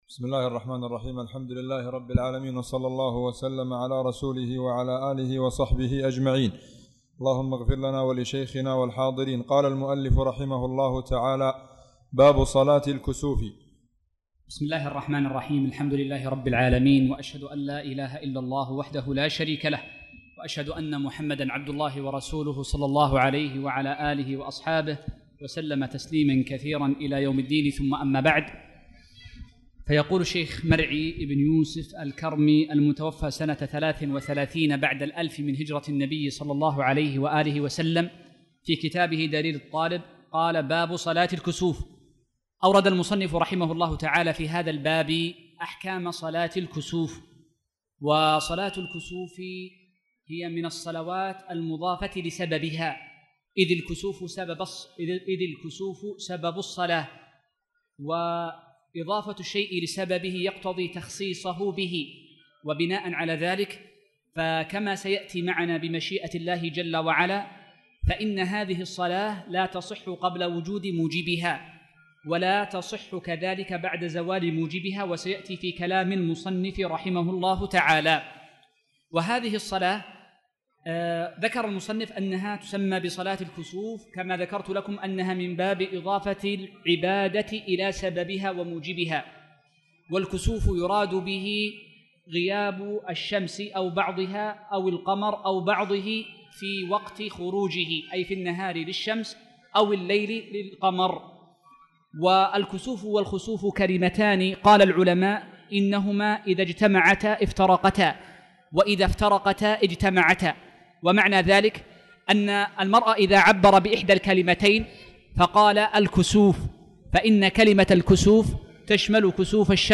تاريخ النشر ٢٨ شوال ١٤٣٧ هـ المكان: المسجد الحرام الشيخ